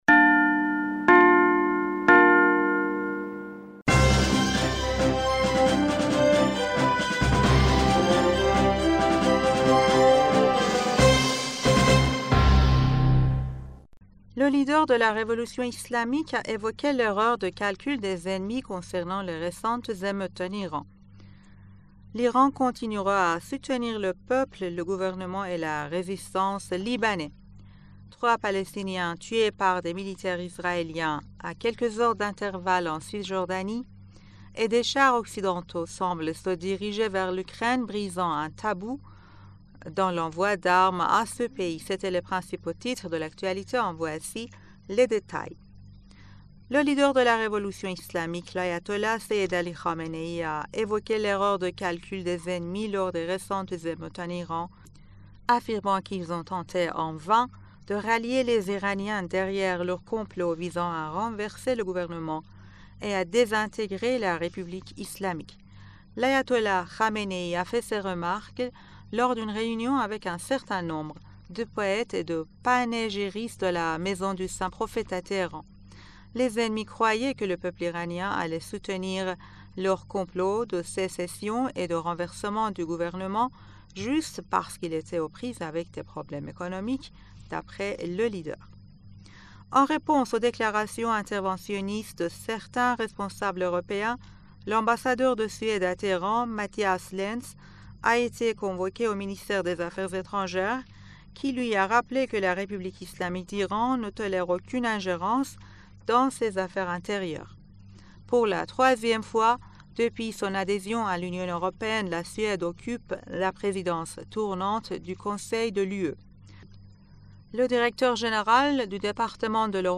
Bulletin d'information du 13 Janvier